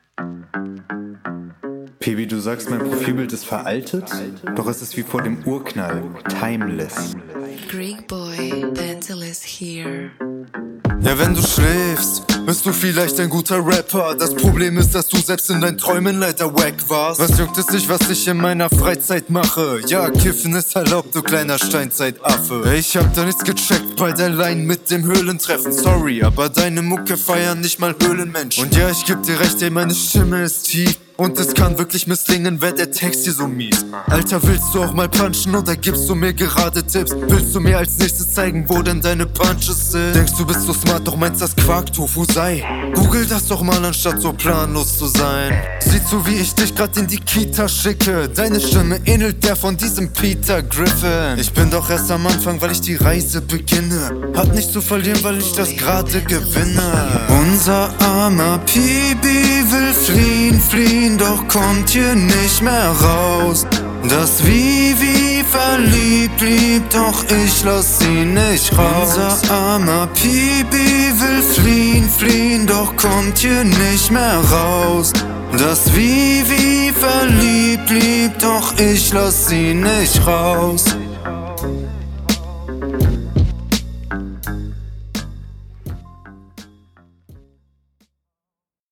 Das Intro hätte ich besser gefunden, wenn du es als Lines auf dem Beat gerappt …
Ganz gut soweit aber cuts hört man zu sehr finde ich.